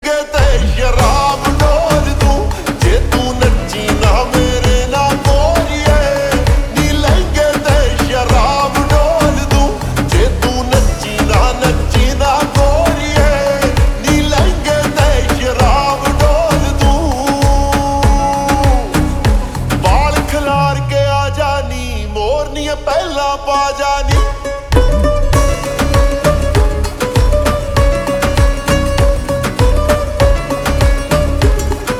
Punjabi Songs
( Slowed + Reverb)